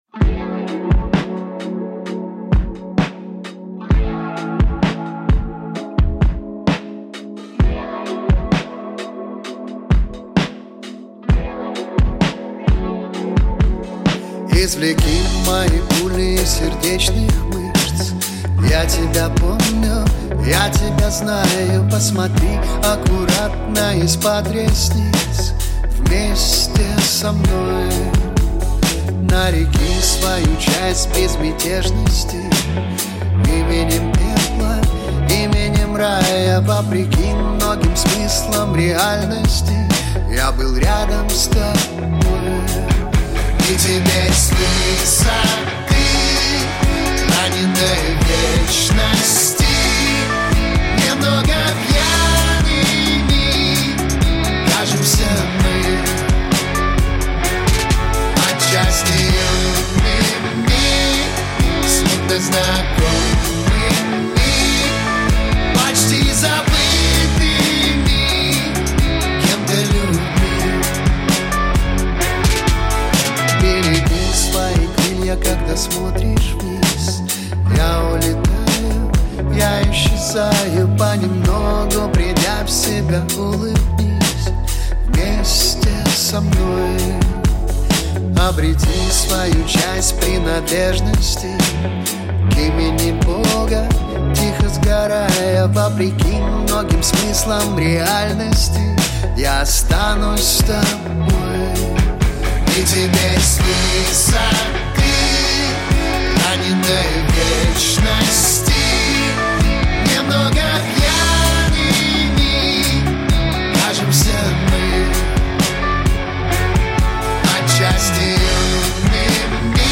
Поп-музыка